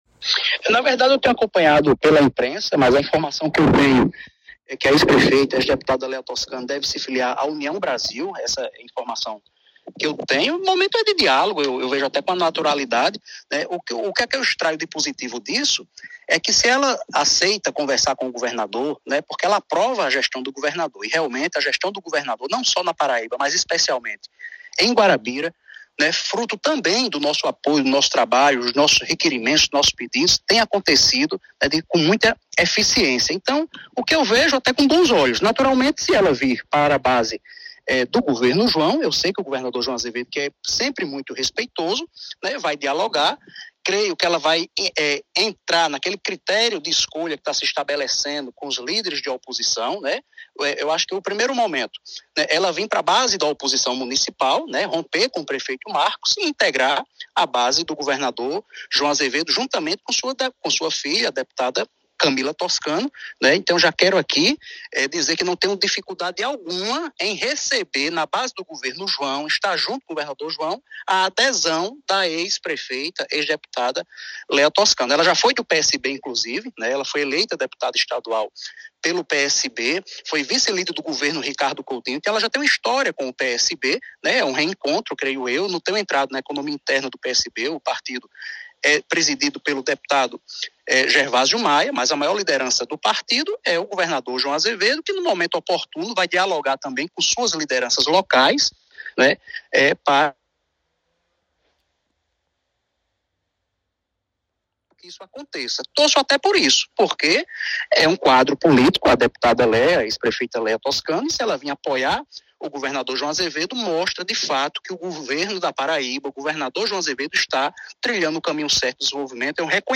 entrevista-raniery-paulino.mp3